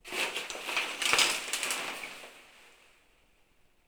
showerCurtainOpen.wav